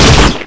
GelShot.ogg